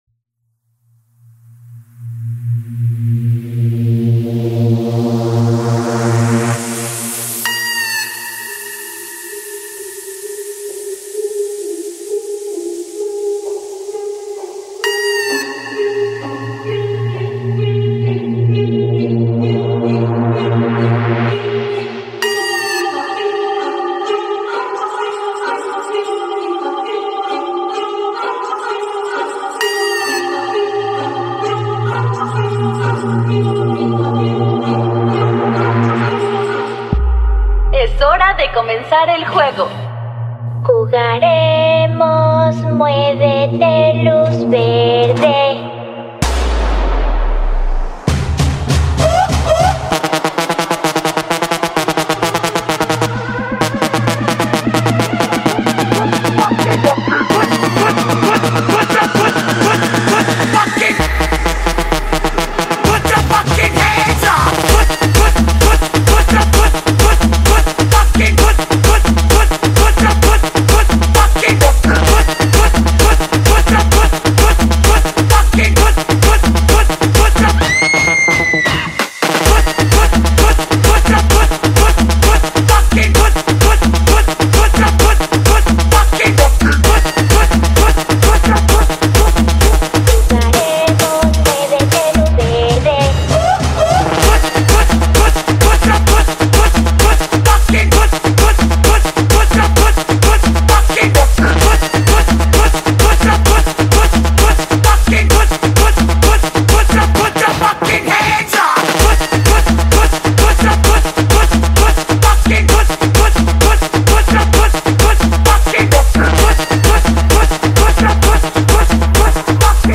میکس بیس دار
تریبال نابی با چاشنیه فیلم